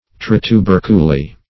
Trituberculy \Tri`tu*ber"cu*ly\, n. [Pref. tri- + L. tuberculum